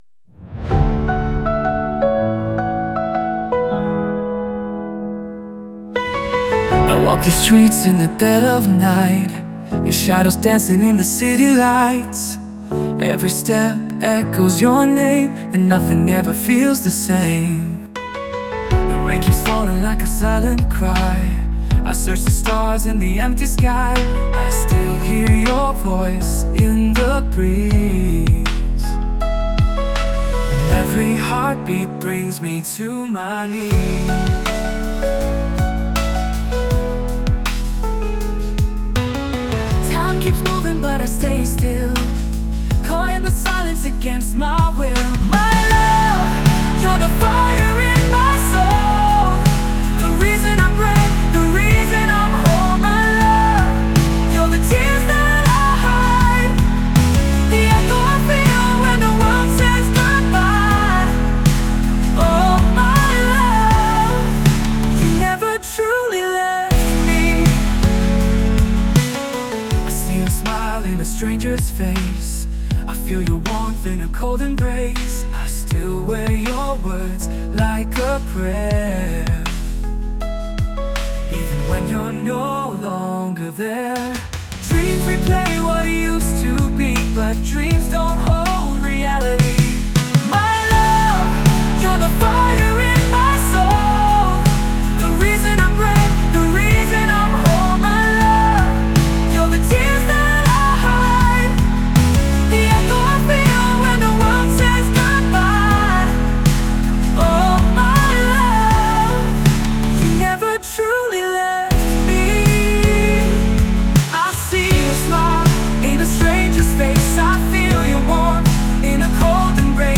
DUETTO